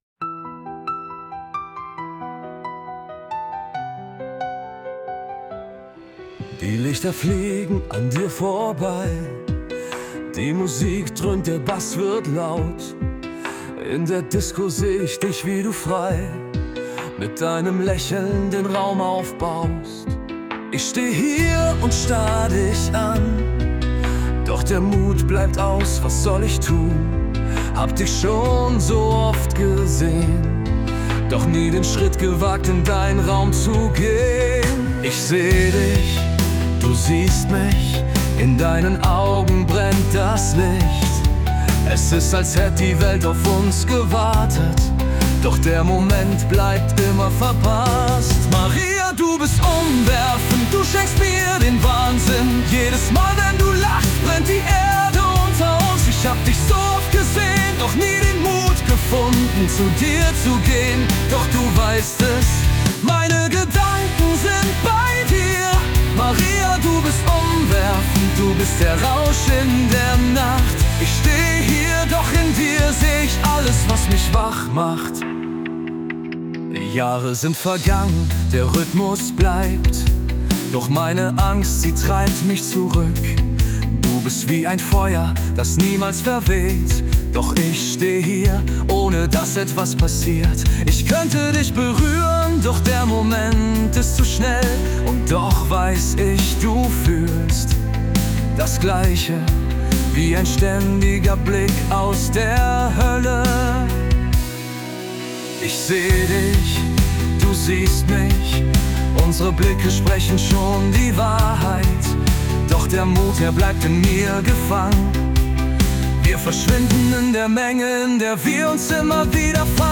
Weder Song, Genre und Text entsprechen meinem Geschmack, aber es ist echt verrückt was KI kann.👍